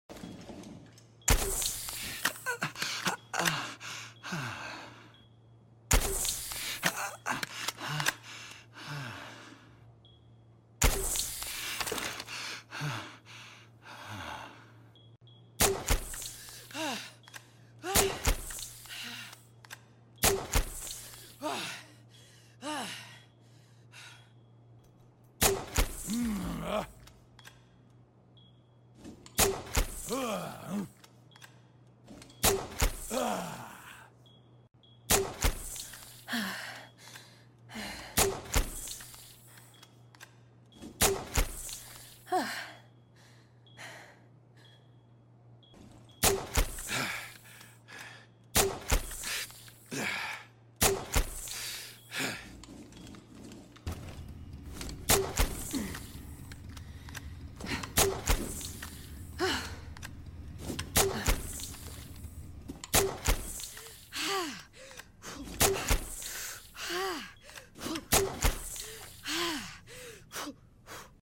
Doc stim sounds in rainbow sound effects free download
Doc stim sounds in rainbow six siege